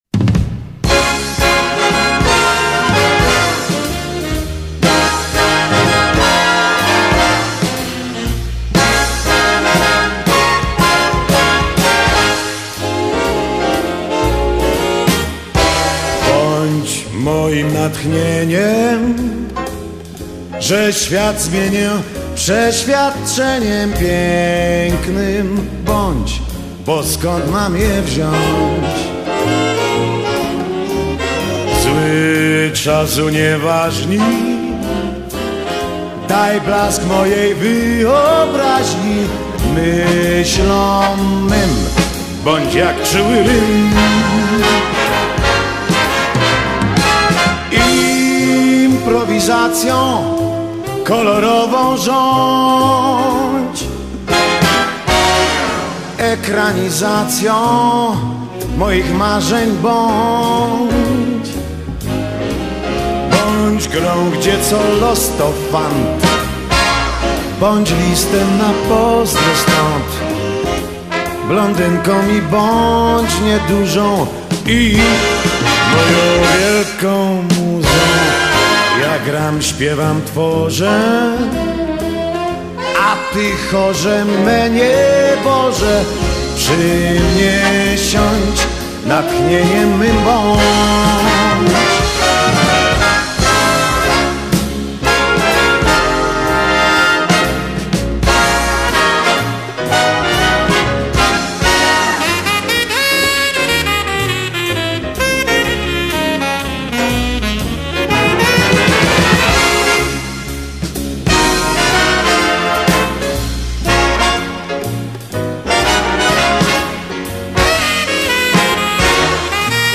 VOC GUITAR KEYB BASS DRUMS     TEKST